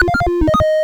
retro_beeps_collect_item_08.wav